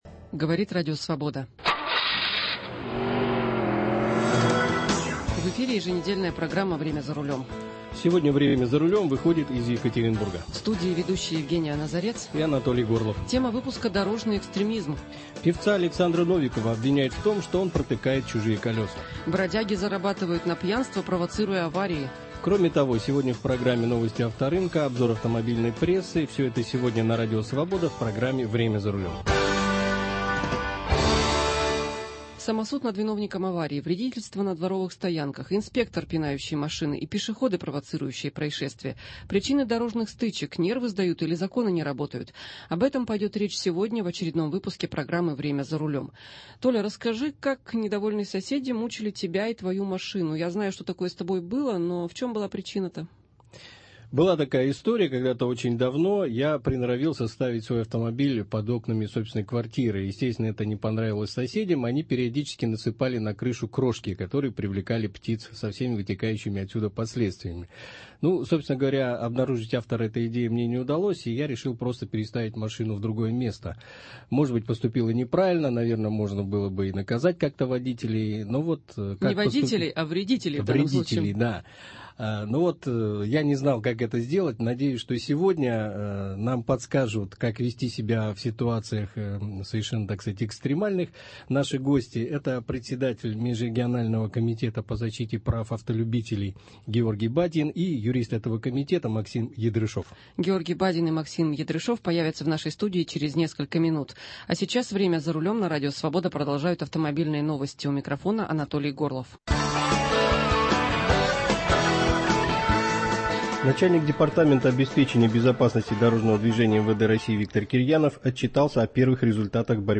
Самосуд над виновником аварии, вредительство на дворовых стоянках, инспектор, пинающий машины, и пешеходы, провоцирующие происшествия. Причины дорожных стычек: нервы сдают или законы не работают? Гости студии